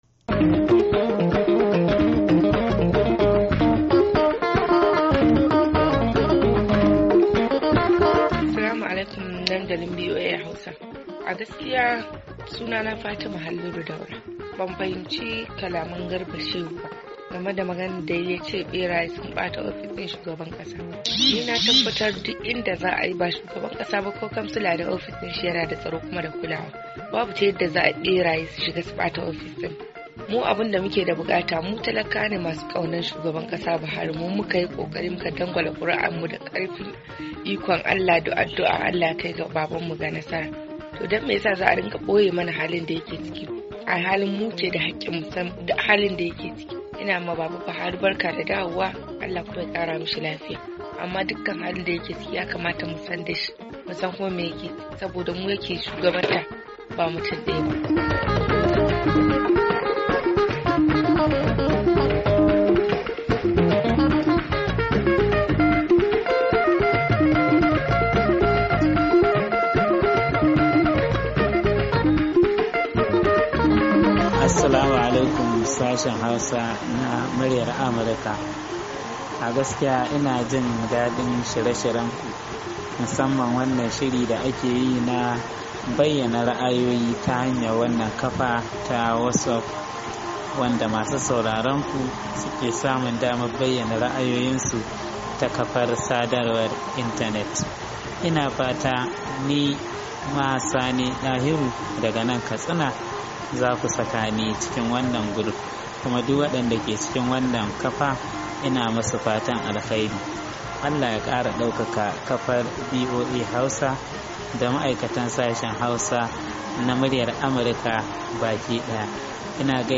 Sakonnin Muryoyin Masu Sauraro Daga Kafar WhatsApp